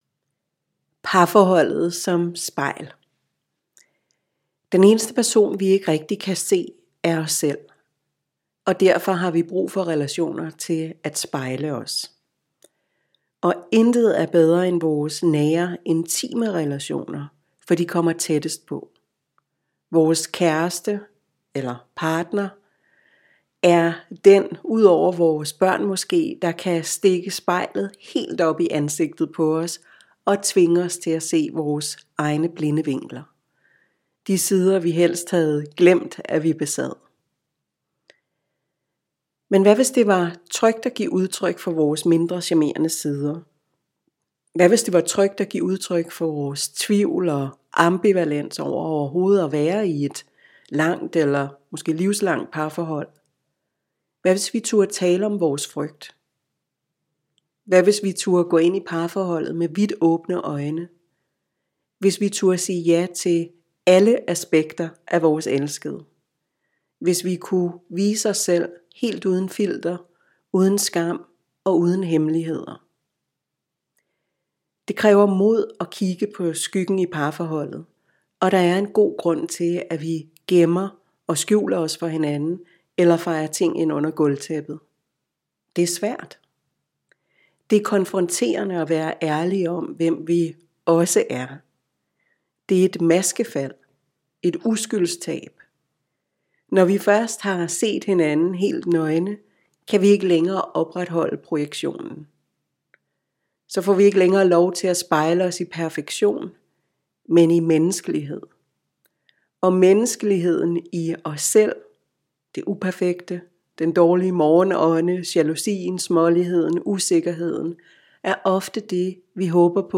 er et trin-for-trin audiokursus, der hjælper jer fra misforståelser og bebrejdelser til ærlig kommunikation og dyb intimitet.